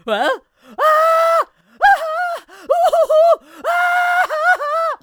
traf_screams1.wav